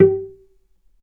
vc_pz-G4-mf.AIF